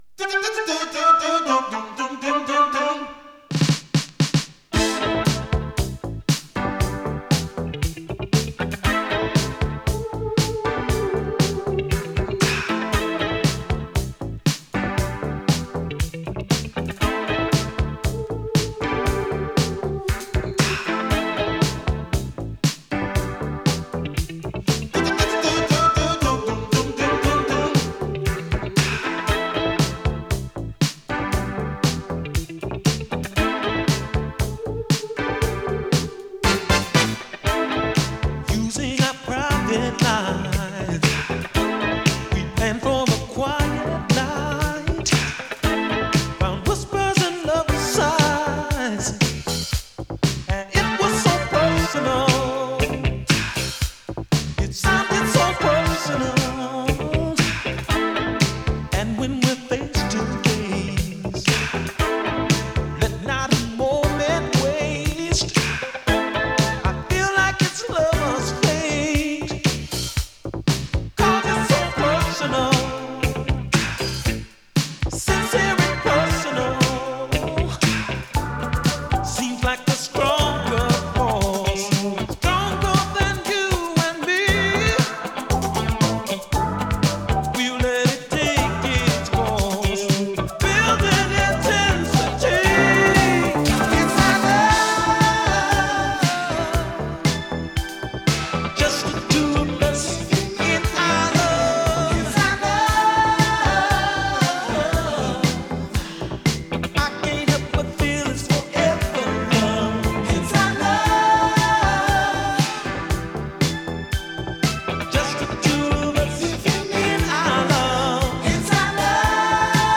込み上げ 哀愁 アーバン ダンサー
切なく込み上げる哀愁アーバン・ダンサー！